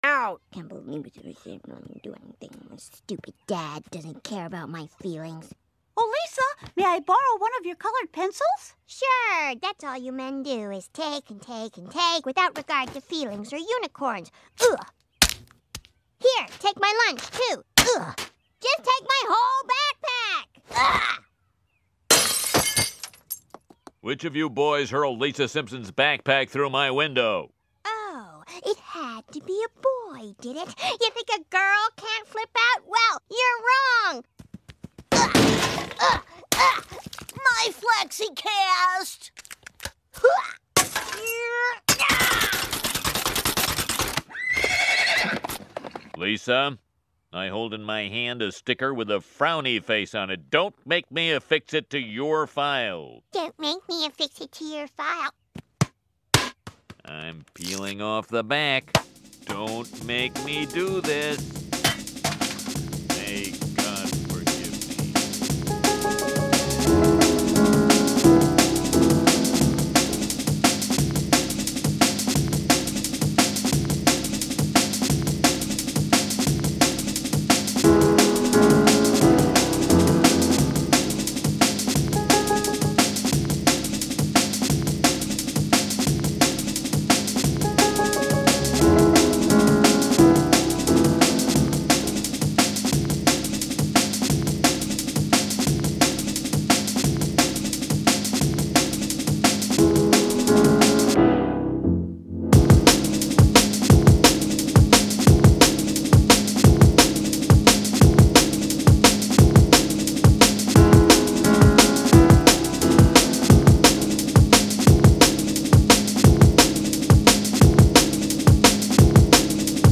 emotional dance music